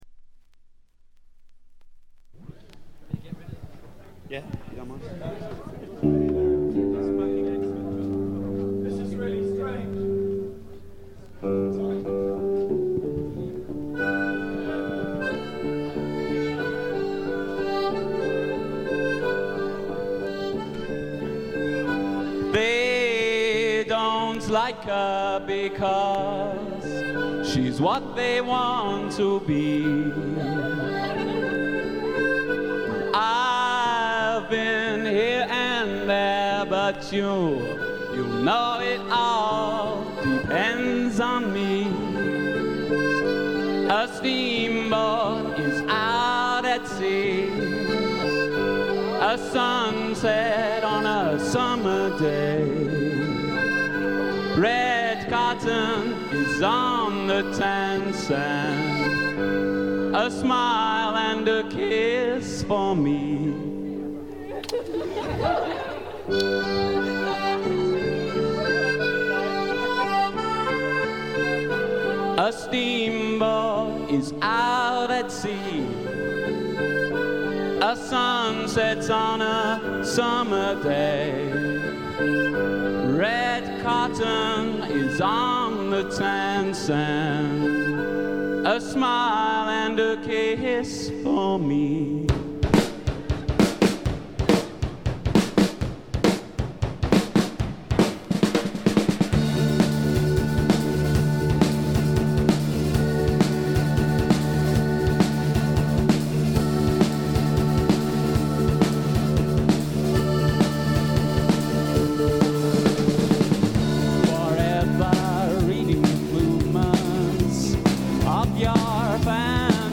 ところどころでチリプチ。
英国のバンドの小さなクラブでののライヴ盤です。
素敵なフォーク・ロックを聴かせてくれます。
全編に鳴り響くメロデオンのチープな音がいい感じですね。
試聴曲は現品からの取り込み音源です。
Recorded live at Moles Club, Bath: September 1988.